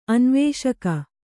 ♪ anvēṣaka